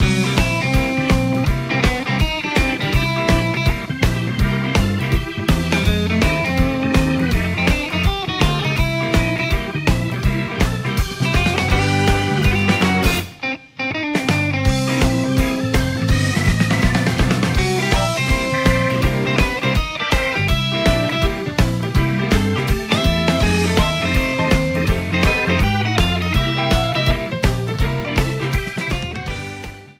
Trimmed, normalized and added fade-out.